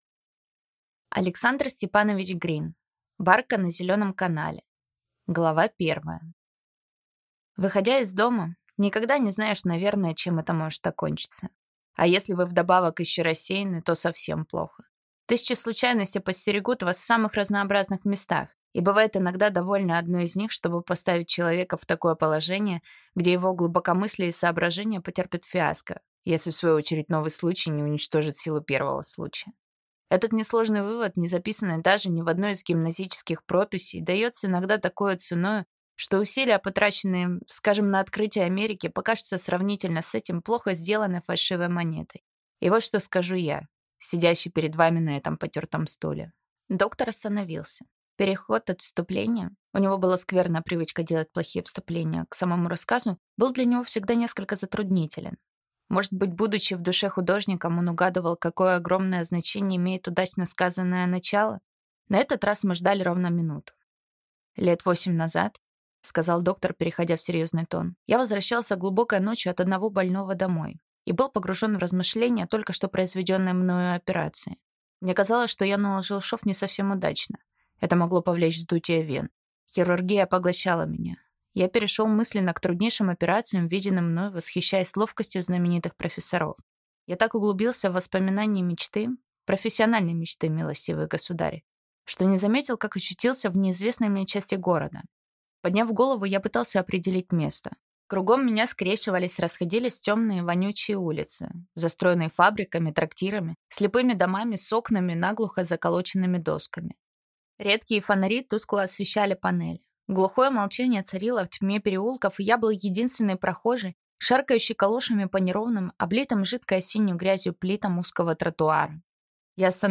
Аудиокнига Барка на Зеленом канале | Библиотека аудиокниг